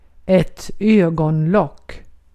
Ääntäminen
IPA : /ˈaɪlɪd/